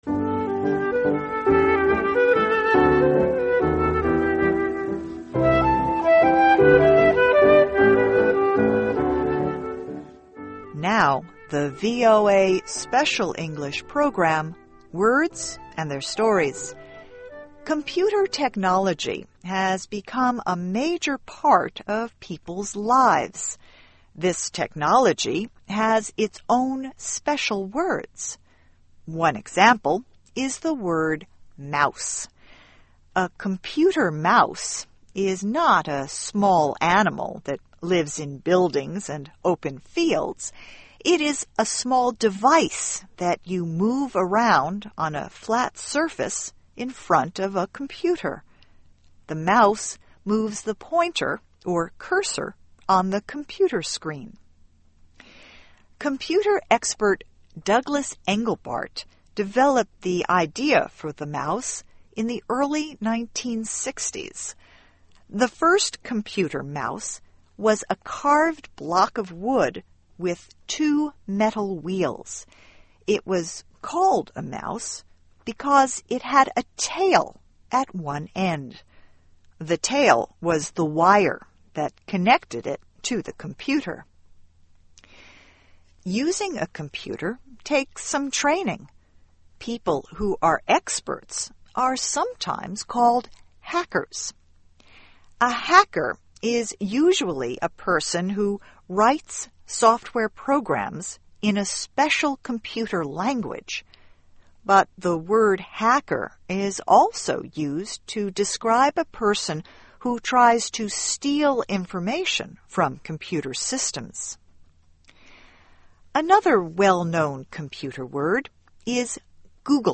Words and Their Stories: Computer Words: Mouse, Blog, Spam and Googling (VOA Special English 2009-04-18)